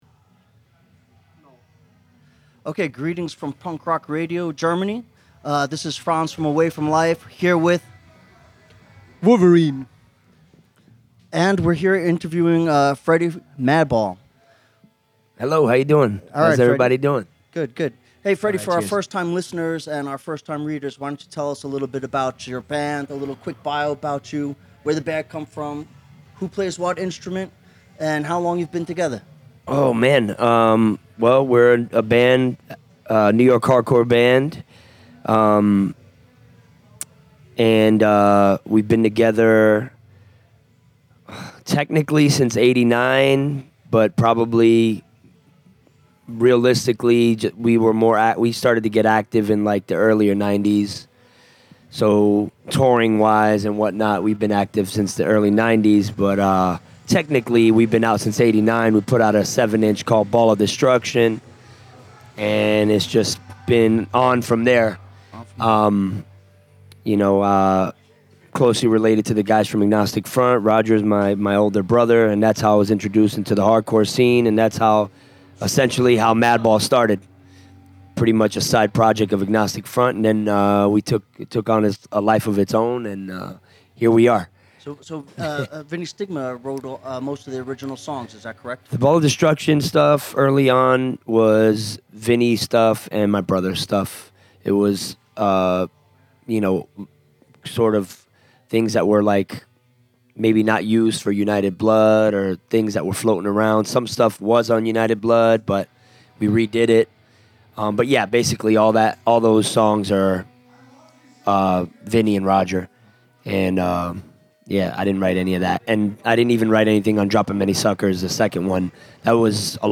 Letzte Episode Interview mit Madball @ Punk Rock Holiday 1.7 9. August 2017 Nächste Episode download Beschreibung Teilen Abonnieren Zusammen mit den Kollegen vom Away From Life Fanzine haben wir beim Punk Rock Holiday 1.7 ein Interview mit Freddy Cricien von Madball geführt.
interview-mit-madball-punk-rock-holiday-1-7-mmp.mp3